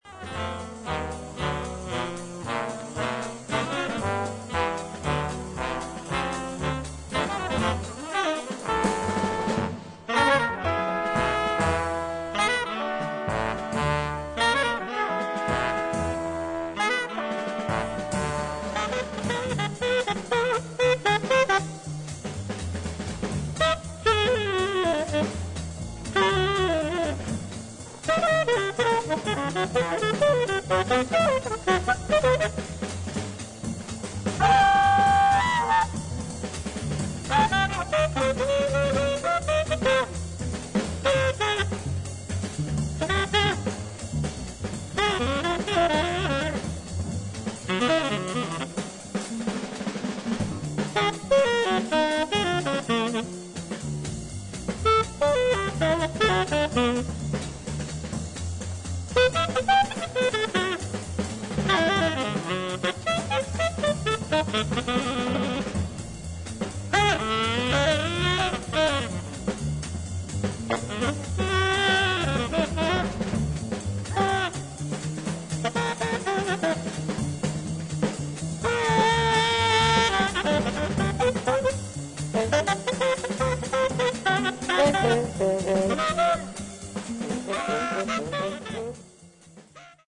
ブルースのモードを基調にしながらも、型に縛られない自由な演奏が魅力の名盤です。